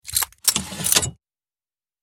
Звук перезарядки базуки ракетой